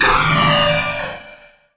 AmpFire2.wav